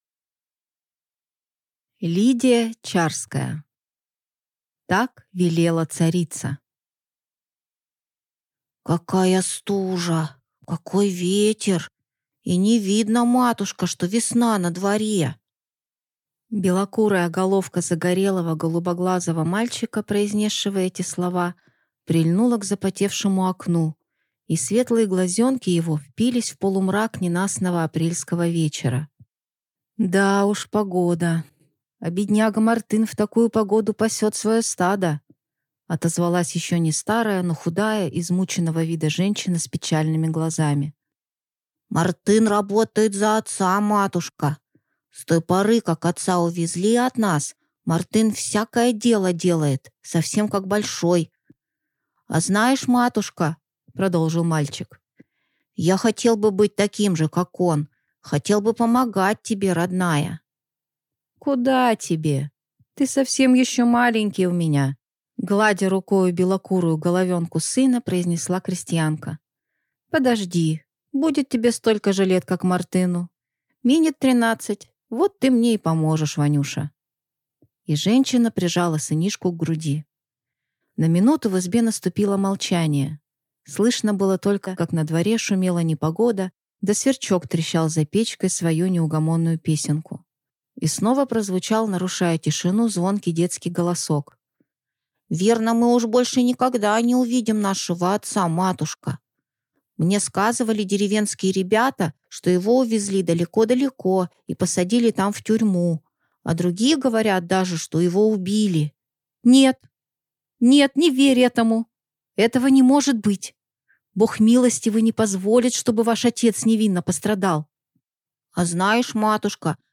Aудиокнига Так велела царица